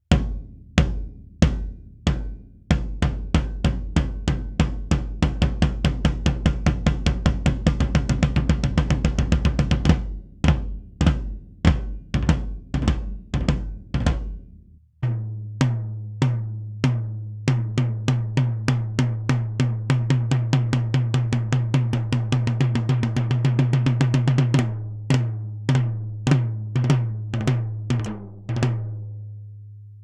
Schlagfell: Amba coated, Reso CS Black dot.
Audio Technica Pro 25
m88, m201 und pro25 haben richtig Druck beim tiefen Tom, wobei mir das pro25 da schon zu viel dröhnt.
Beim mittleren Tom gefällt mir das pro25 gar nicht, weil es irgendwie ausgehöhlt klingt.